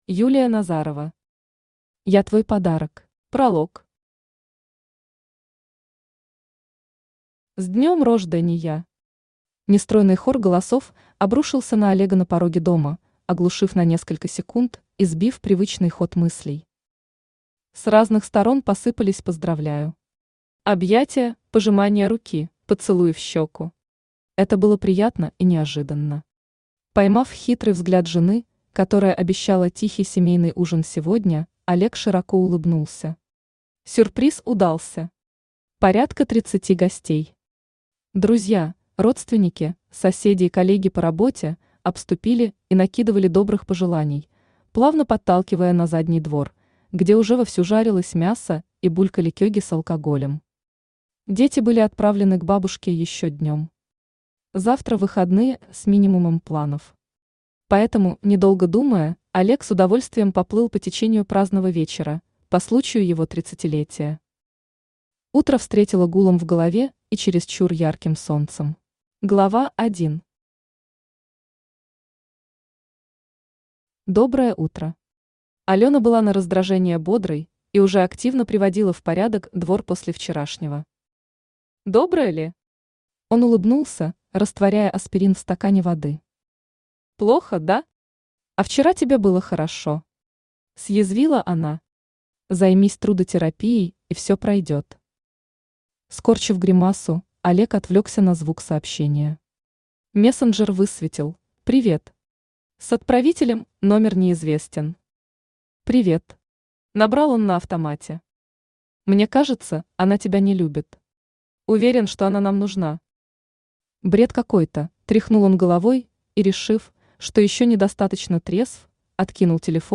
Аудиокнига Я твой подарок | Библиотека аудиокниг
Aудиокнига Я твой подарок Автор Юлия Назарова Читает аудиокнигу Авточтец ЛитРес.